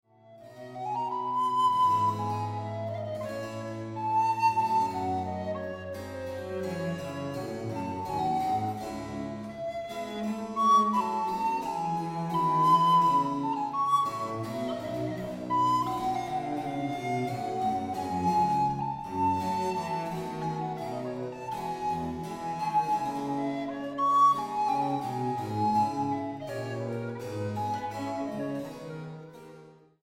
Música Barroca Mexicana